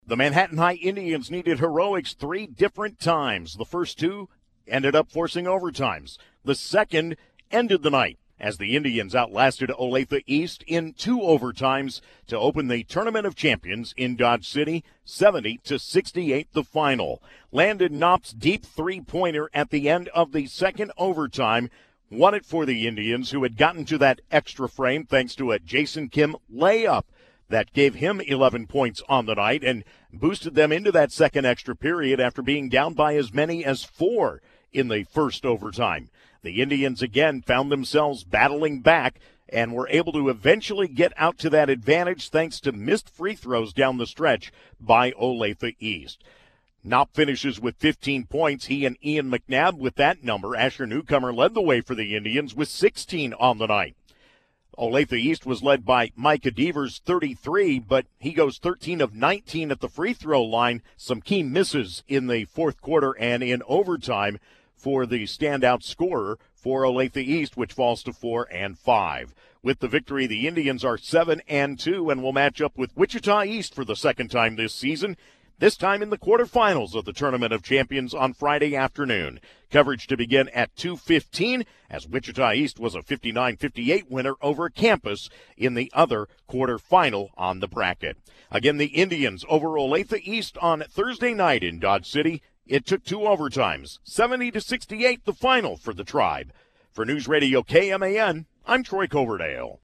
MHS-Boys-Olathe-East-recap.mp3